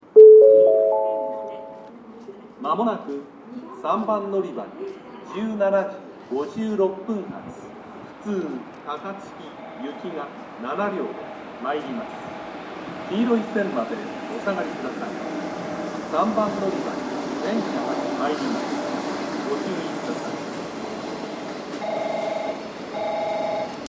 この駅では接近放送が設置されています。
音量は大きめで収録がしやすいですね。
接近放送普通　高槻行き接近放送です。